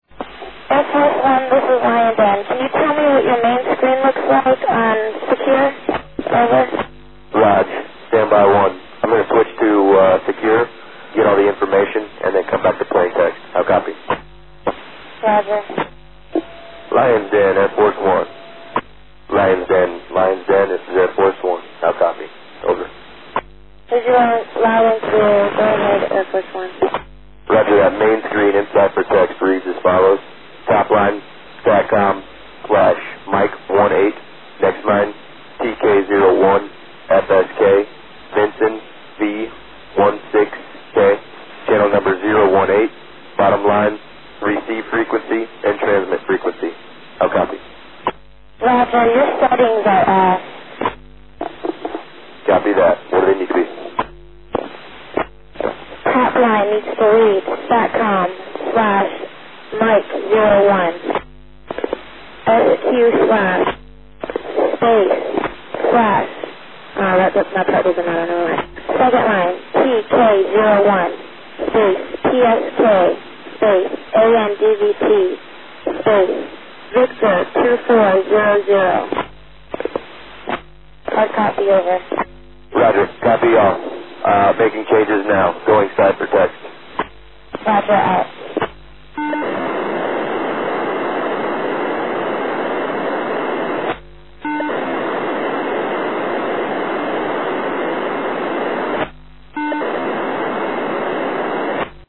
199 Кб 10.09.2011 12:54 M01 SQ/ TK01 PSK ANDVT V2400 РУБИН